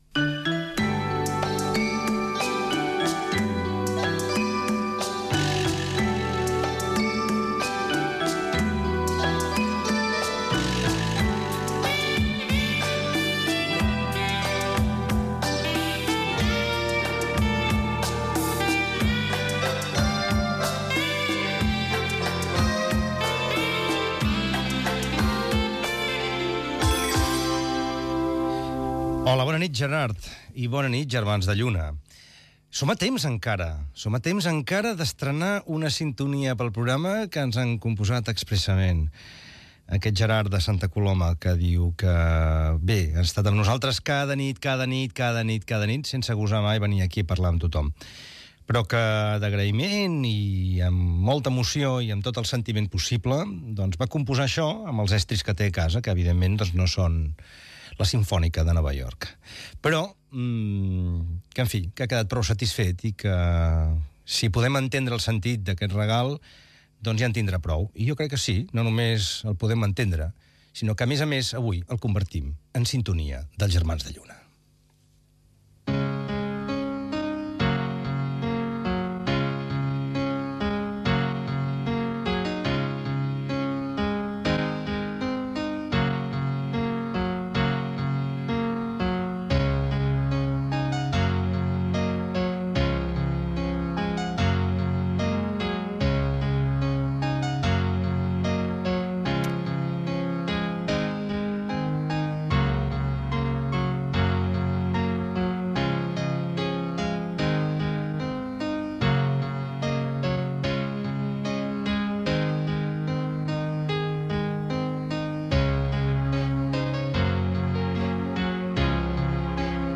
Indicatiu de l'emissora, comentari sobre la sintonia del programa que ha composat un oïdor, composició musical, telèfon de participació, tema musical, tema del programa "El temps", missatge escrit d'un oïdor i tema musical
Entreteniment